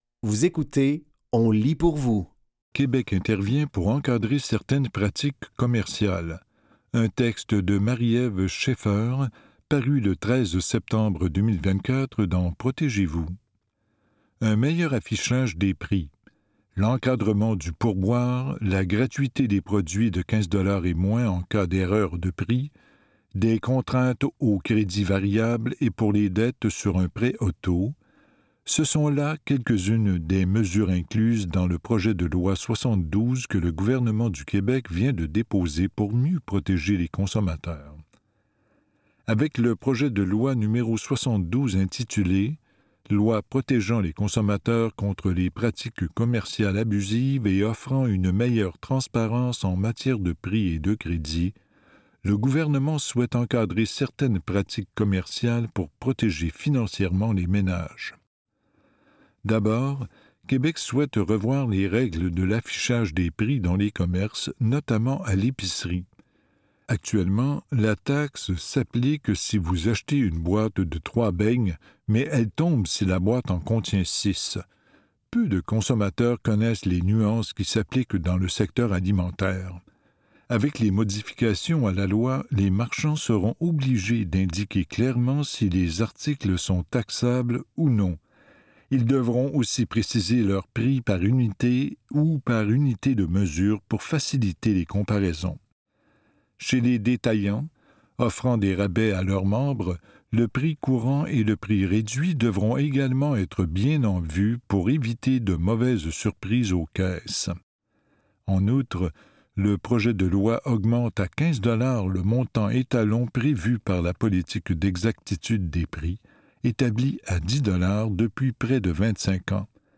Dans cet épisode de On lit pour vous, nous vous offrons une sélection de textes tirés du média suivant : Protégez-Vous, La Presse et Québec Science.